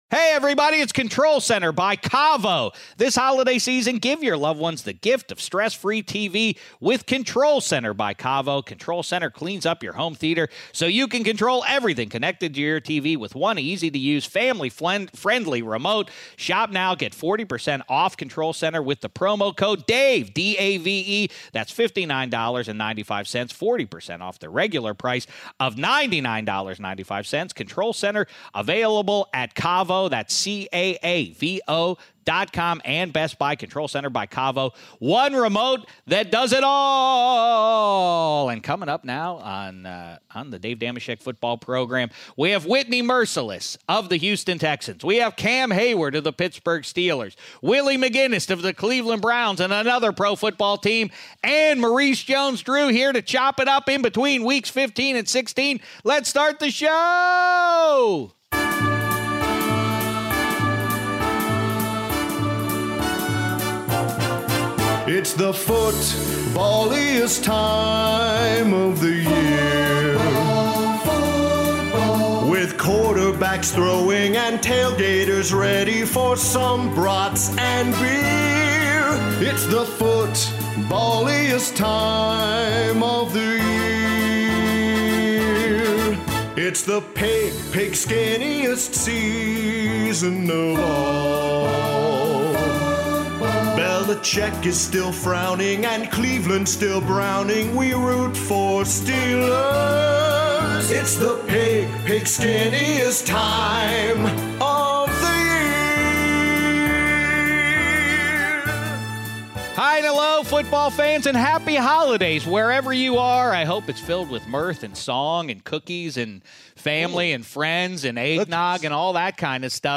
Dave Dameshek is joined in Studio 66 by Maurice Jones-Drew who kibitzes with Shek about the Los Angeles Rams' issues following their loss at home to the Philadelphia Eagles (1:49). Next, Man Of The Year candidate and Steelers DT Cam Heyward joins the show to talk about the great charity work he has been doing as well as their big win over the Patriots last Sunday (19:17). Then, Willie McGinest drops by the studio to chat about the Patriots struggling in their loss to the Steelers (29:33).